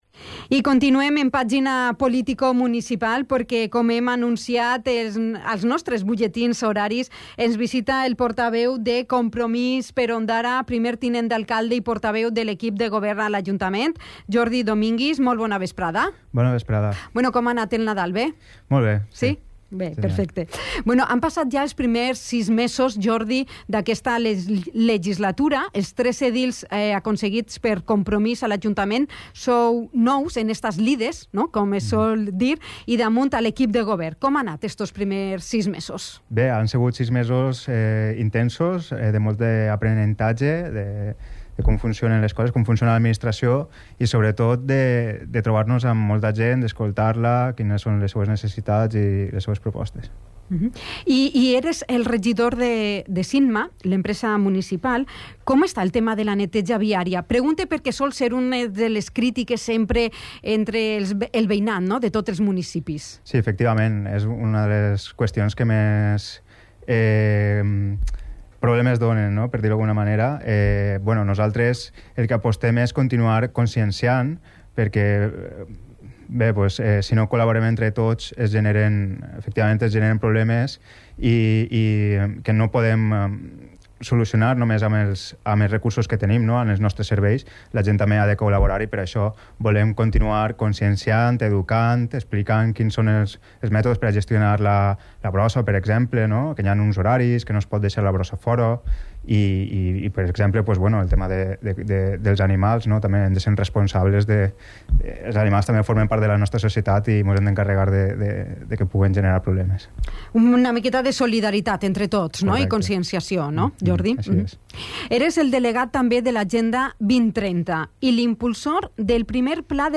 Así lo ha señalado su portavoz municipal, además de portavoz del equipo de gobierno y primer teniente de alcalde en el Ayuntamiento ondarense, Jordi Dominguis, en la entrevista mantenida en Dénia FM, para realizar balance de la gestión municipal y propuestas para 2024.